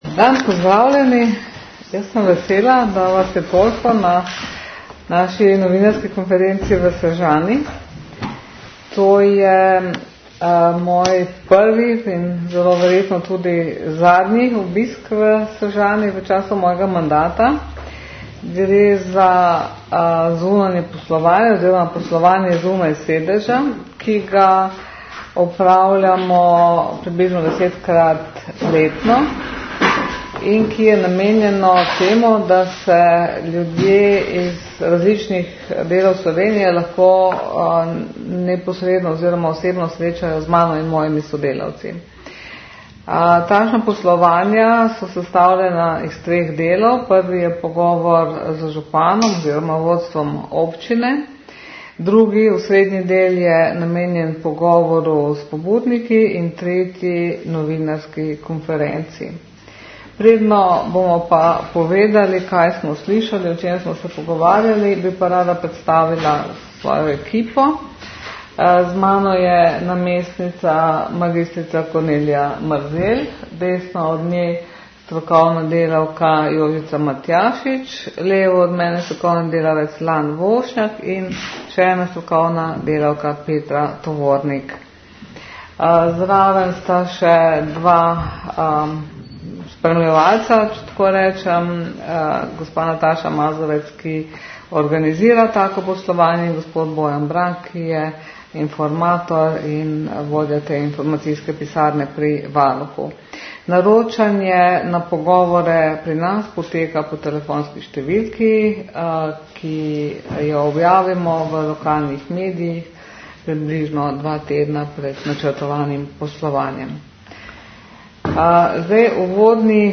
Zvočni posnetek novinarske konference
Varuhinja je po pogovorih svoje ugotovitve predstavila na krajši novinarski konferenci.